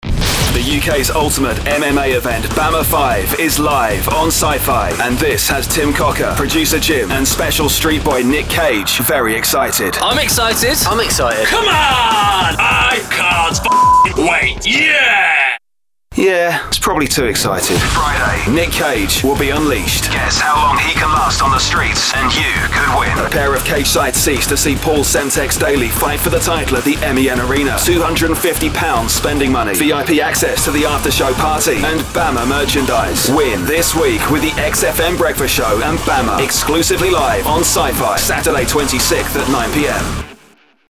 From cool & credible to energetic sports commentator. A hip, young sell for your product.